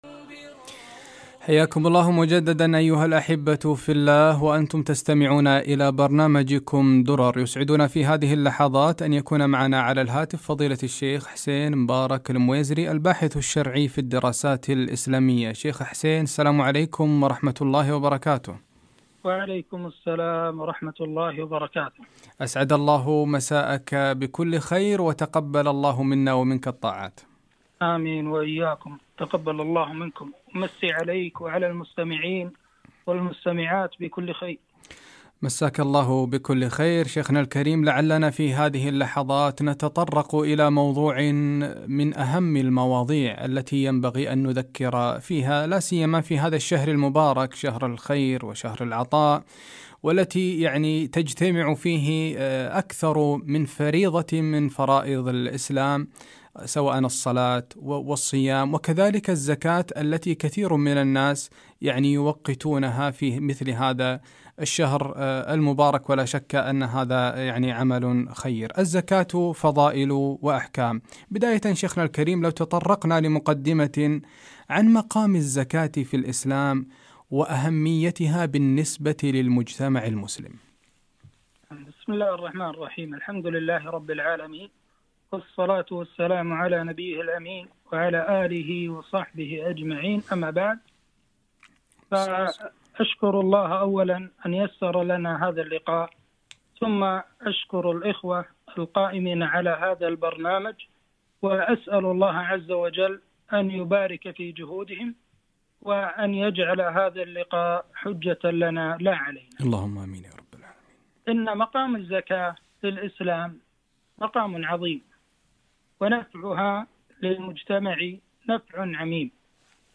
الزكاة فضائل وأحكام - لقاء إذاعي